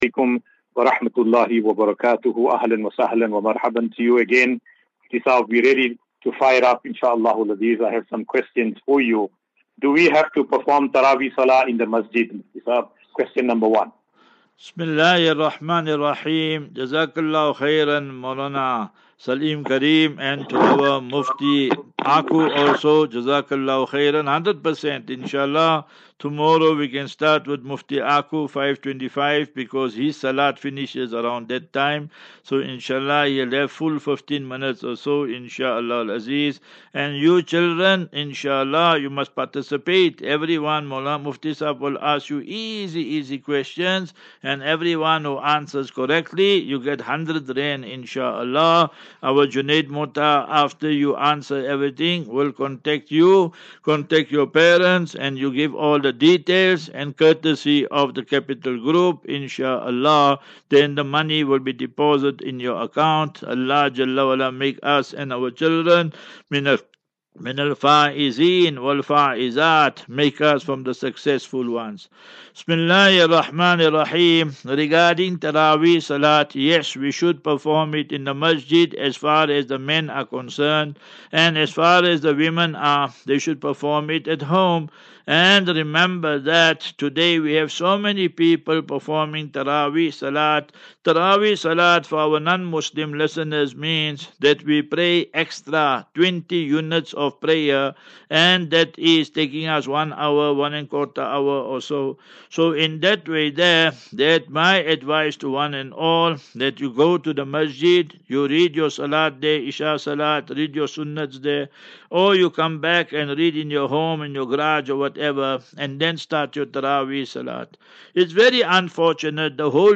As Safinatu Ilal Jannah Naseeha and Q and A 12 Mar 12 March 2024.